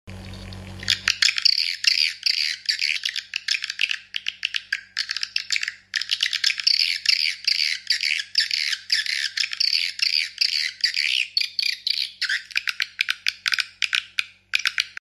Audax Horn Tweeter AX 8 sound effects free download
Audax Horn Tweeter AX-8 [For Swiftlet Farming]
✅Loudness